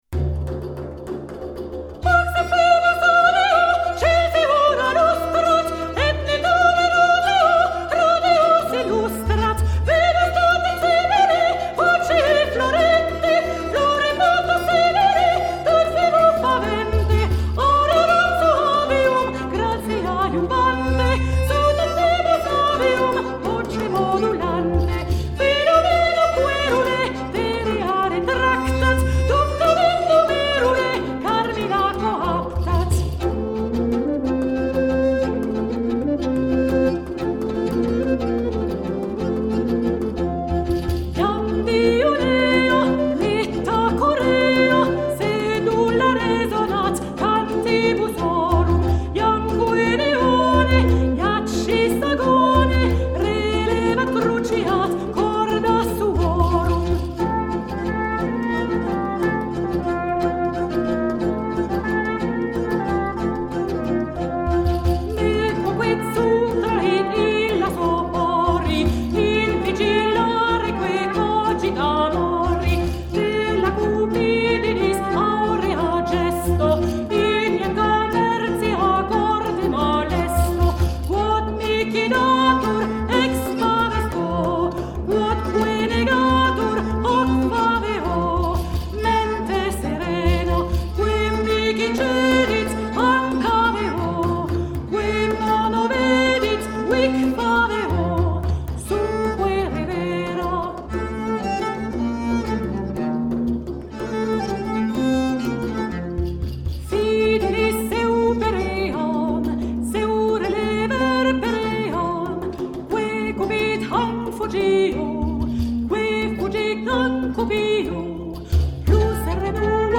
Dans la mesure du possible nous mettons en espace ce programme Carmina Burana: (plusieurs places pour le choeur, déambulation, 2 postes pour l’orchestre.)